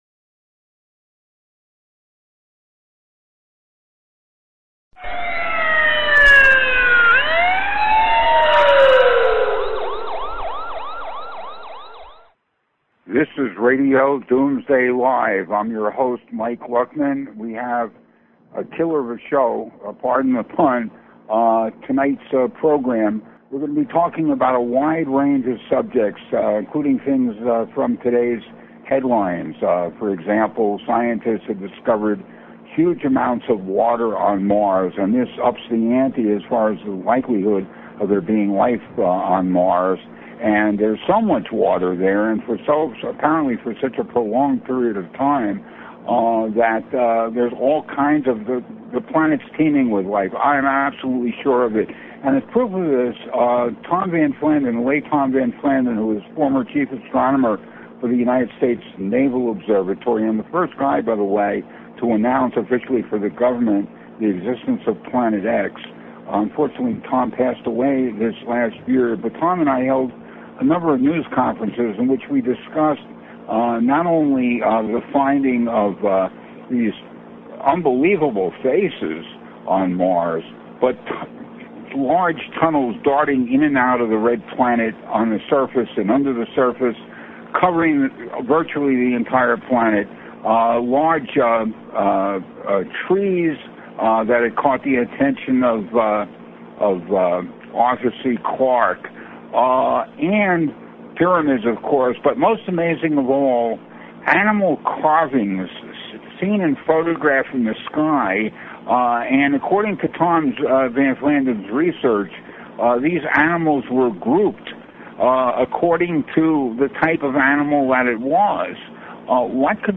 Talk Show Episode, Audio Podcast, Starship_One_Radio and Courtesy of BBS Radio on , show guests , about , categorized as